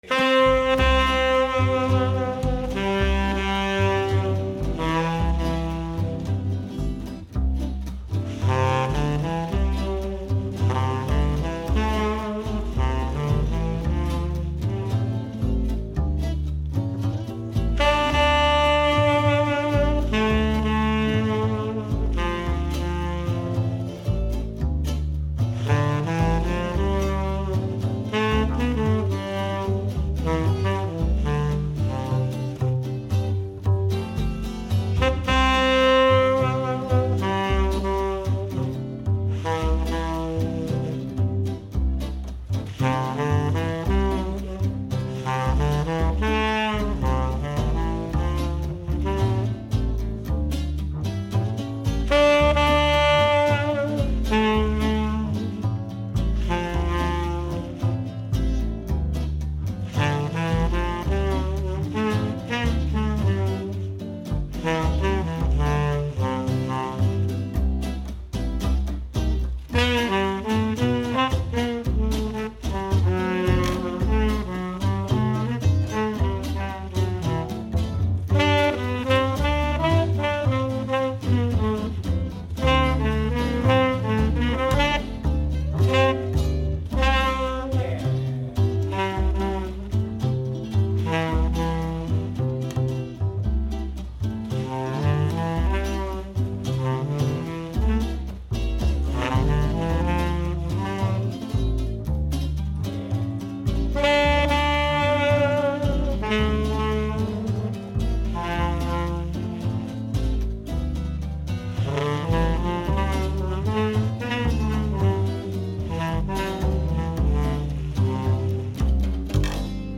at Preservation Hall in New Orleans!